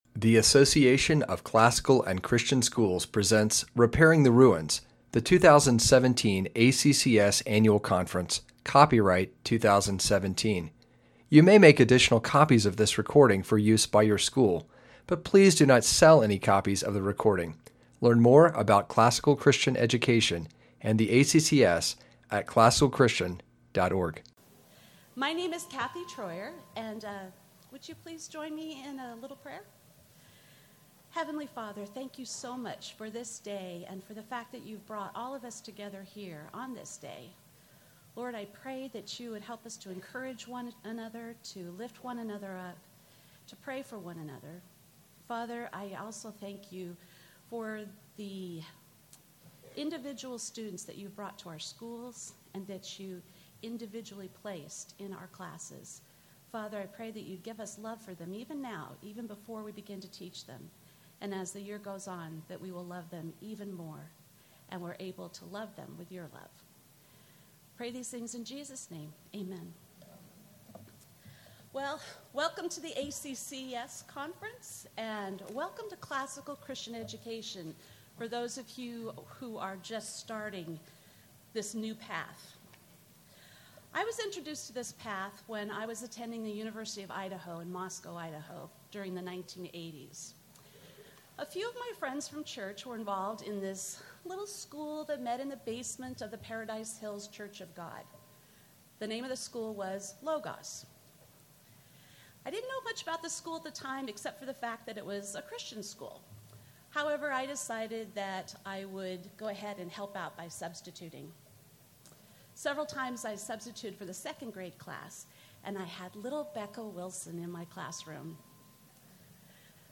2017 Foundations Talk | 1:00:39 | K-6, History, Latin, Greek & Language, Literature
Additional Materials The Association of Classical & Christian Schools presents Repairing the Ruins, the ACCS annual conference, copyright ACCS.